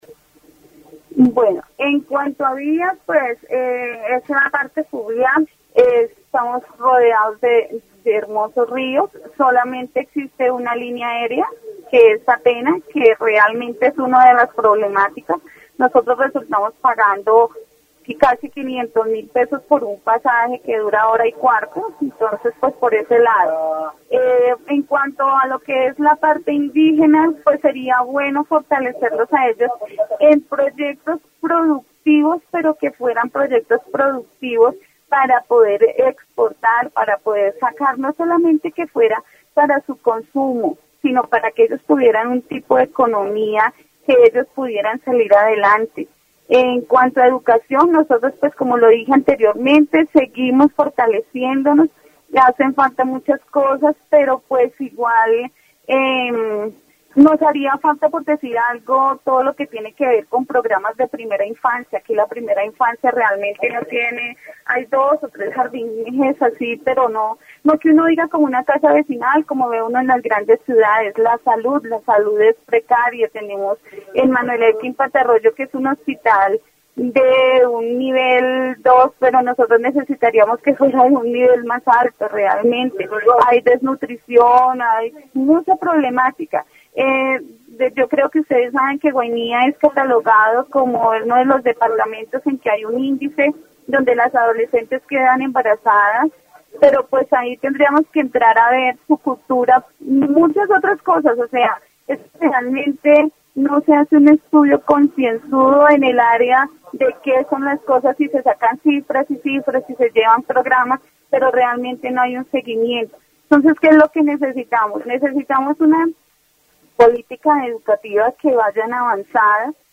Entrevista sobre las problemáticas en Guainía, incluyendo la falta de infraestructura, la necesidad de proyectos productivos y los desafíos en educación y salud.